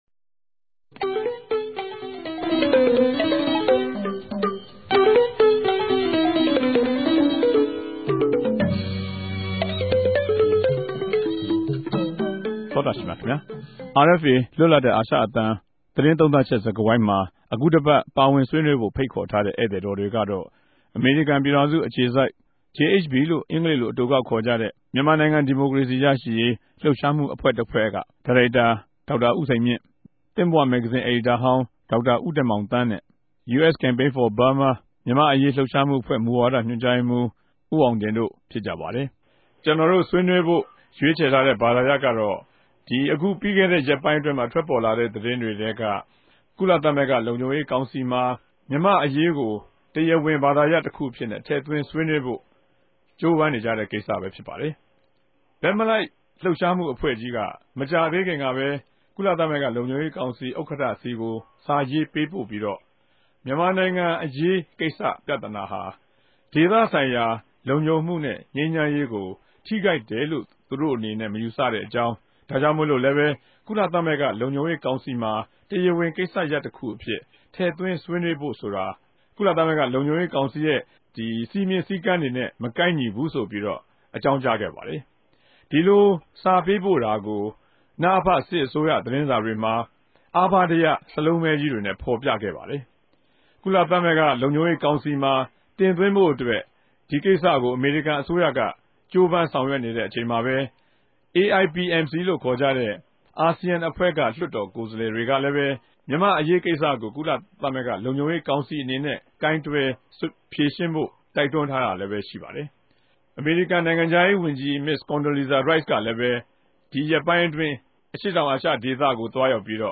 တပတ်အတြင်းသတင်းသုံးသပ်ခဵက် စကားဝိုင်း (၂၀၀၆ ဇူလိုင်လ ၁၆ရက်)